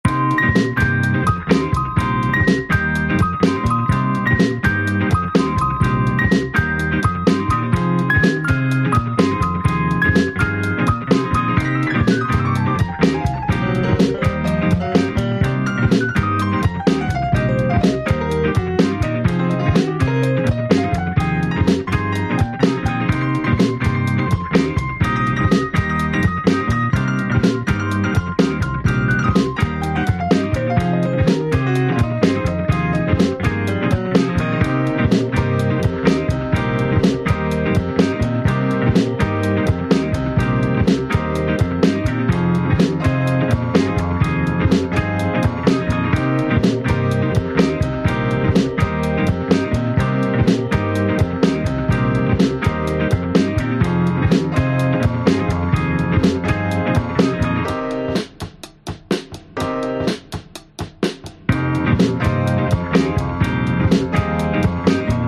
Luister naar de schets van Kinderen:
Intro / Scala Ascendente / Main Theme
Met het muziekdoosje aan het begin probeer ik een slaapliedje-vibe neer te zetten.
Het muziekdoosje speelt volgens ChatGPT een arpeggio van Cø of Cm7(b5) ofwel de noten C, Bb, G, D#, C, D#
Hier begint de piano in een dissonante scale ascendente, waarna die overgaat in een solo vanuit C pentatonisch De gitaar speelt hier twee akkoorden:
Cm7(b5) Fm7
Het hoofdthema bestaat voorlopig ook nog alleen uit de akkoorden Cm7(b5) en Fm7, maar dat is nog open voor interpretatie.